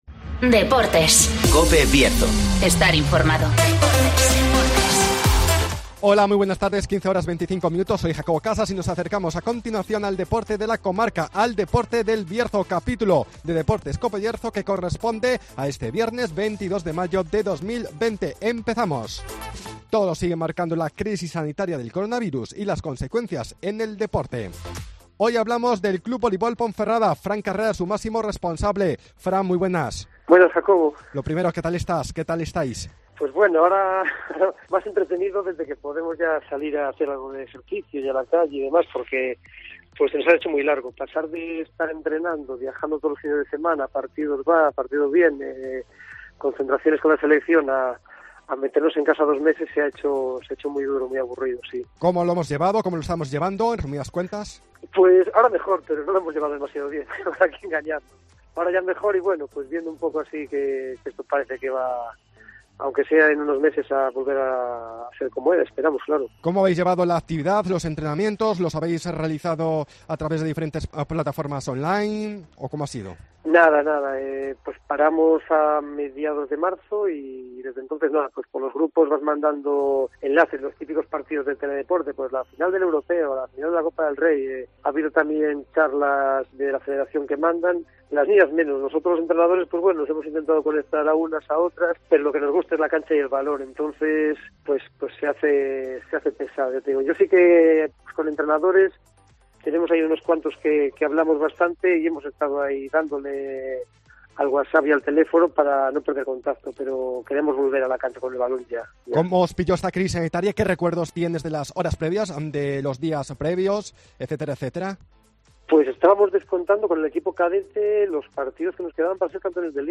Crisis del coronavirus -Entrevista